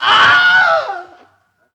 Wilhelm Scream
Category 🗣 Voices
death fall falling famous killed legend legendary male sound effect free sound royalty free Voices